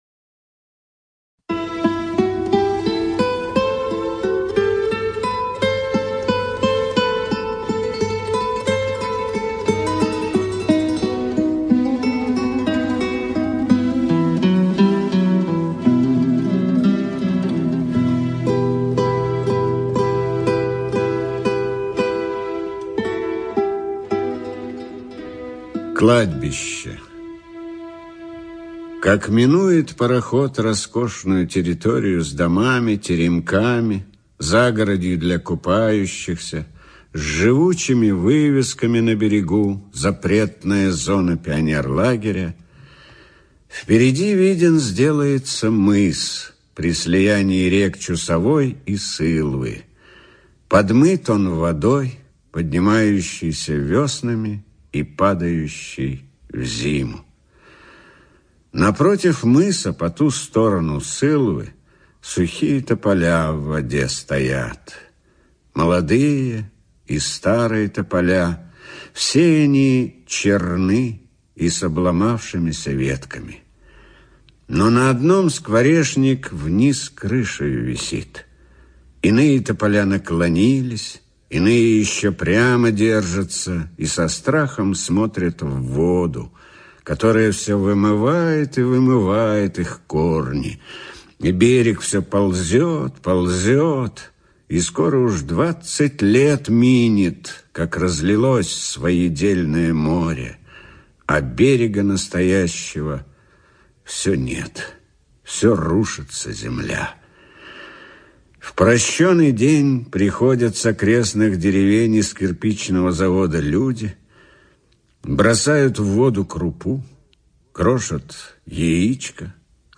ЧитаетКраско И.